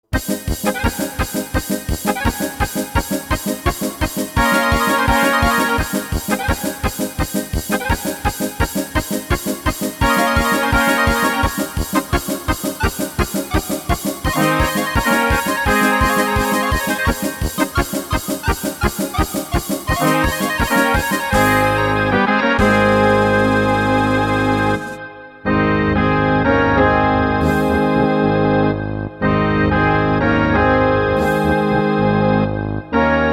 Rubrika: Národní, lidové, dechovka